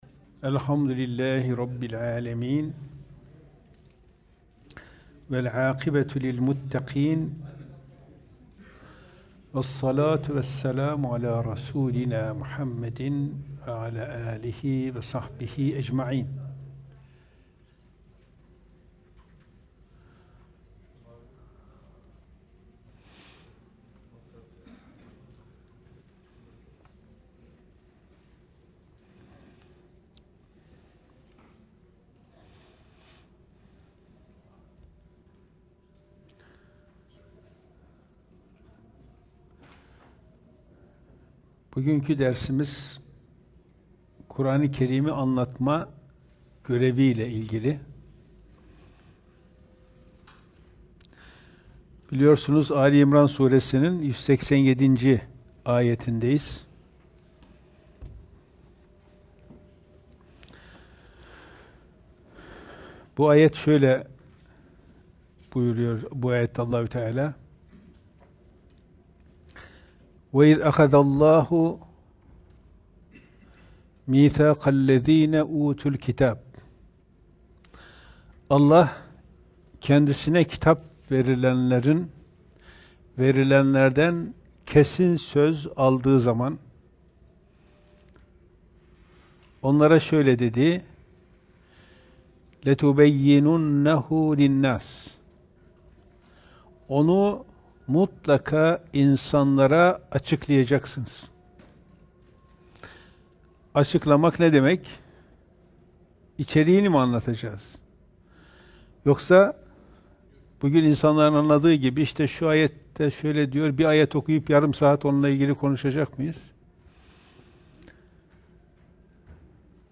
Bugünkü dersimiz Kuran-ı Kerim’i anlatma görevi ile ilgili.